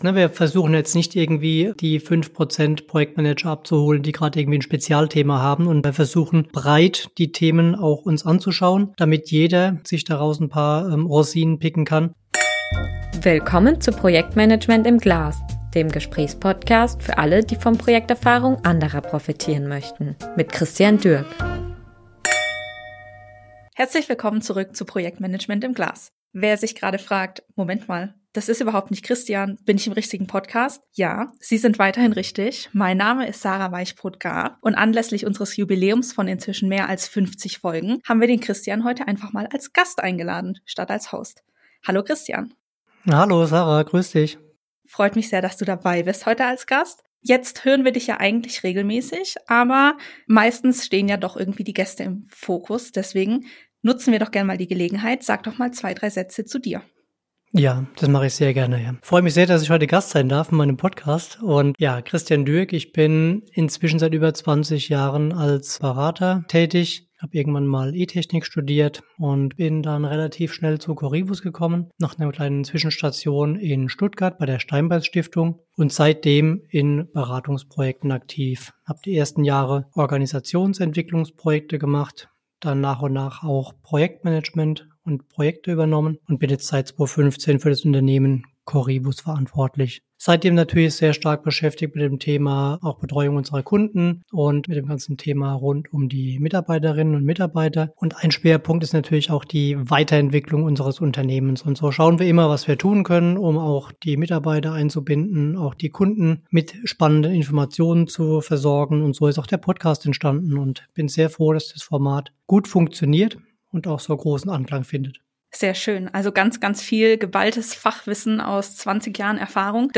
#55 Im Gespräch: Ein Rückblick auf über 50 Folgen Projektmanagement-Podcast ~ Projektmanagement im Glas Podcast